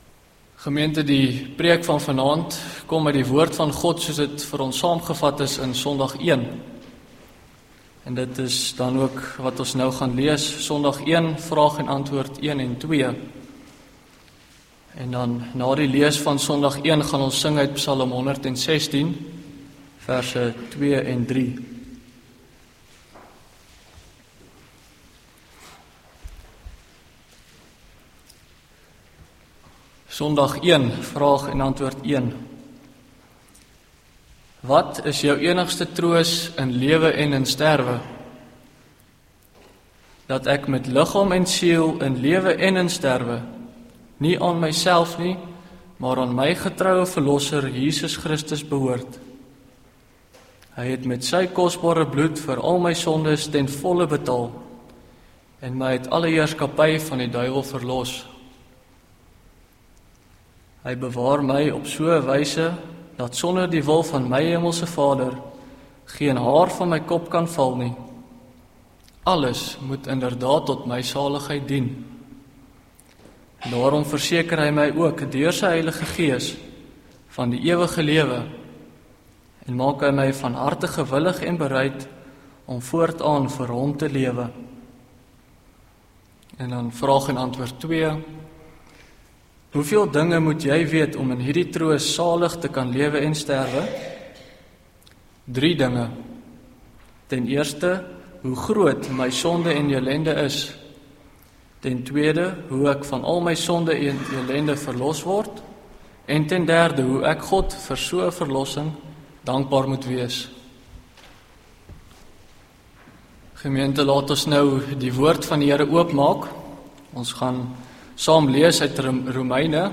Link Preek Inhoud